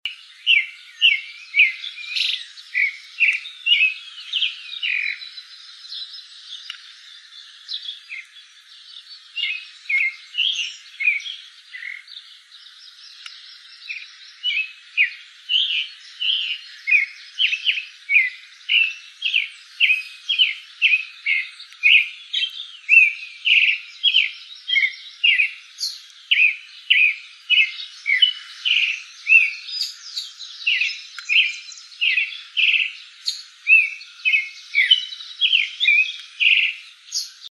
Zorzal Chalchalero (Turdus amaurochalinus)
Nombre en inglés: Creamy-bellied Thrush
Fase de la vida: Inmaduro
Localidad o área protegida: Colonia Carlos Pellegrini
Condición: Silvestre
Certeza: Vocalización Grabada